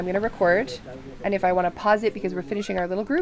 (Various speakers)
Individual group recording for Kamiali Rapid Word Collection.
recorded at 44.1khz/16 bit on a solid state Zoom H4n recorder
Kamiali (Lababia village), Morobe Province, Papua New Guinea